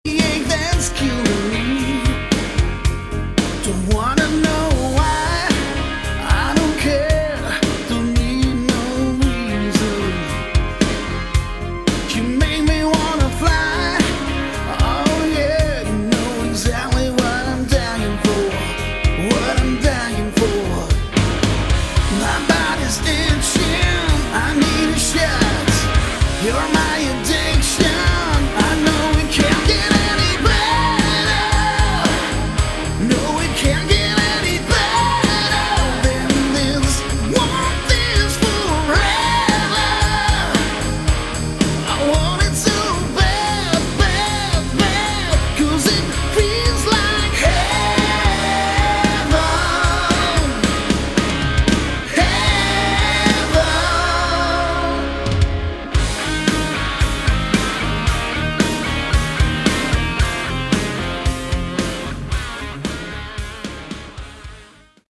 Category: Hard Rock
Vocals
Keyboards
Bass
Drums
Guitar